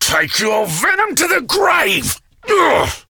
insect2.ogg